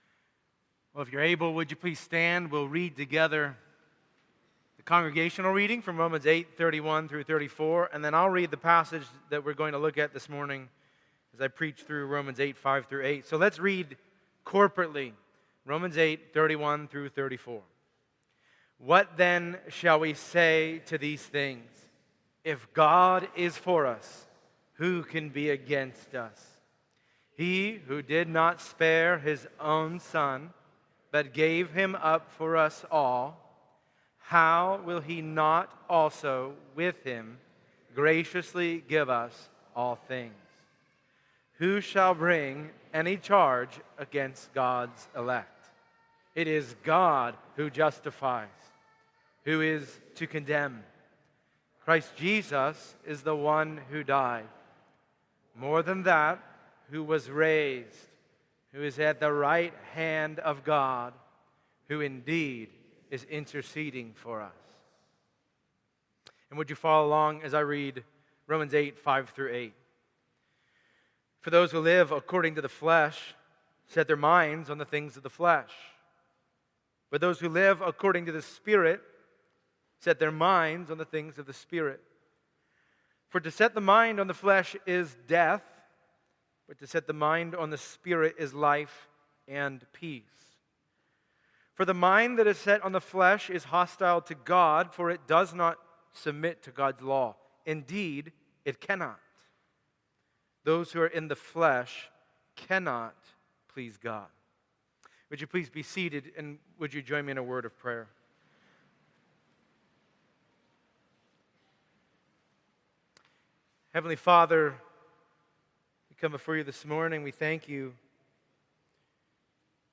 Passage: Romans 8:5-8 Service Type: Sunday Morning Download Files Bulletin « No Condemnation- What?